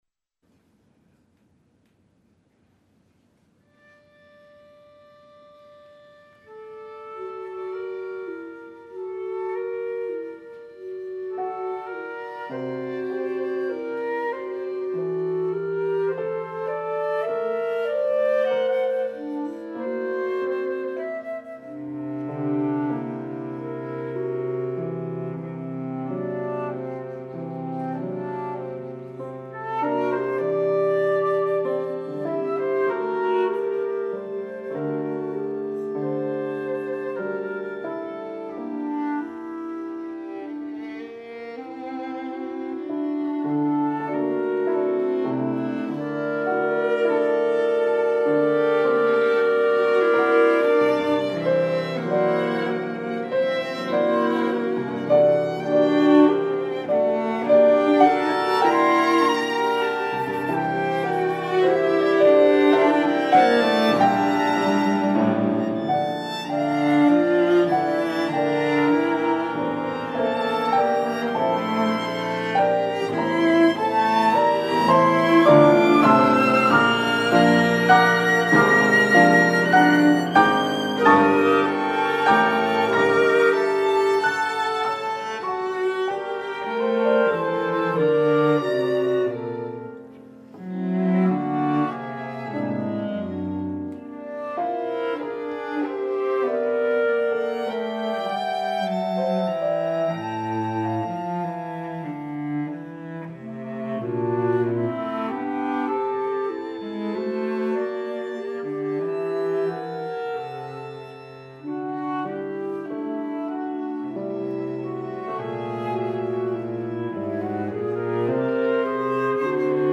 for flute, clarinet, piano, violin, and cello